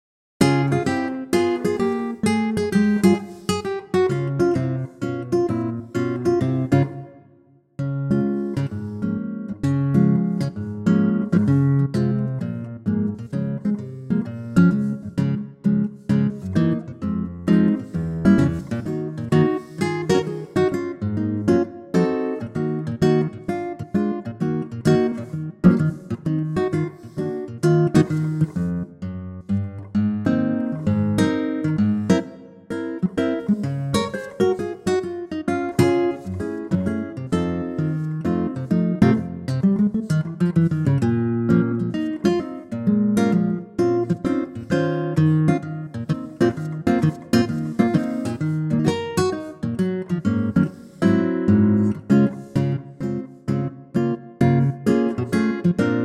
4 bar intro and vocal in at 7 seconds
key - Db - vocal range - Ab to Db (optional Eb)
Wonderful and intimate acoustic guitar arrangement